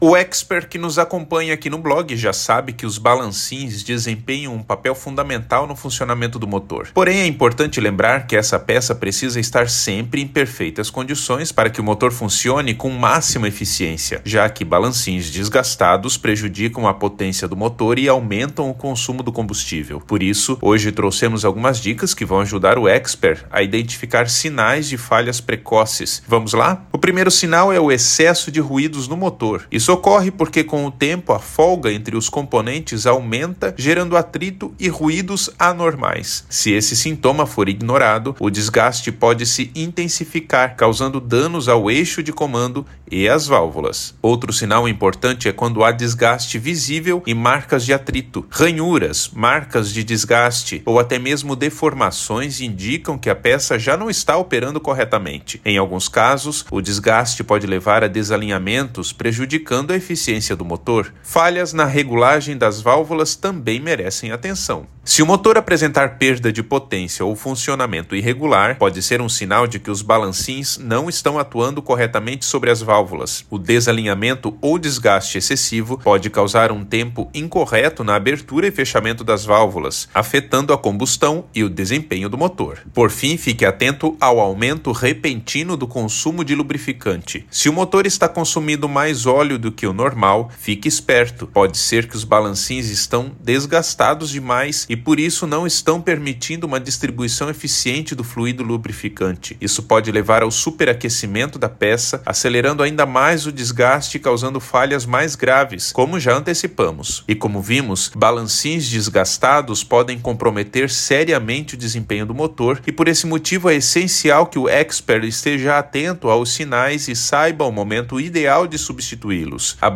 Narracao-02-balancins-desgastados-1.mp3